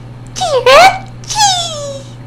Cries